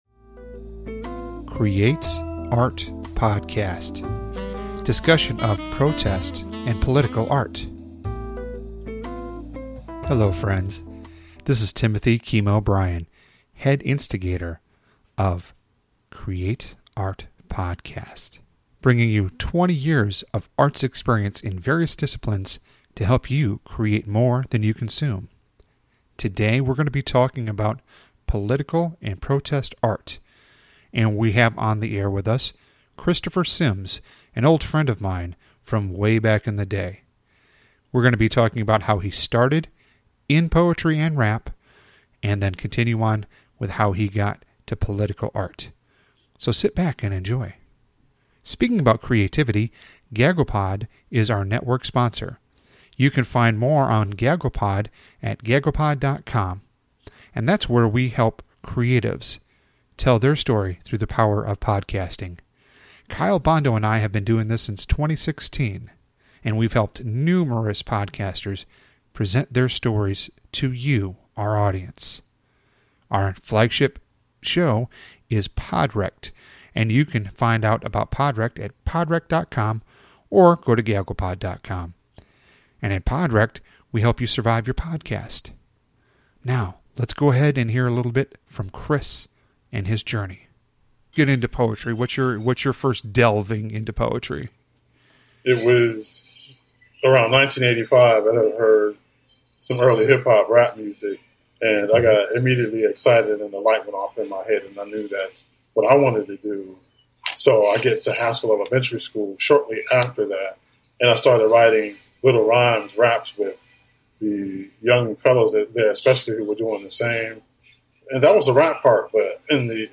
Discussion of Political/Protest Art